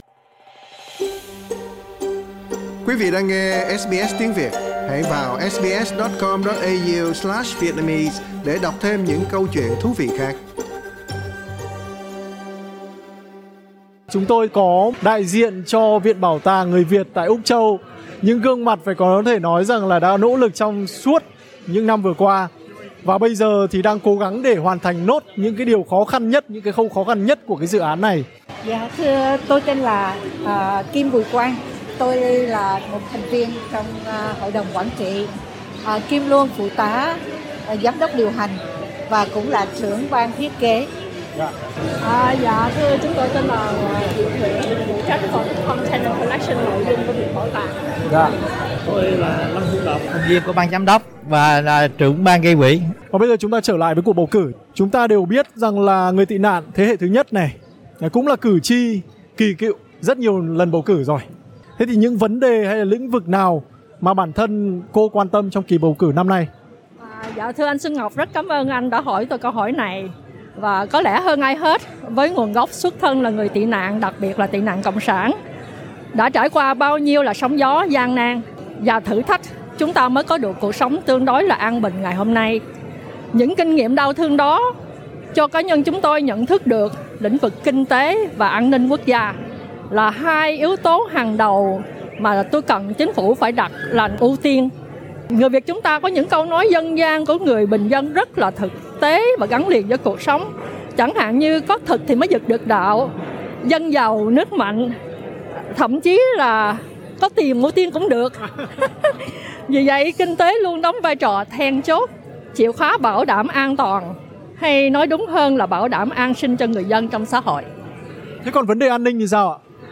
Phỏng vấn diễn ra tại cuộc mạn đàm về bầu cử tại Dandenong Market cuối tuần qua do SBS tổ chức.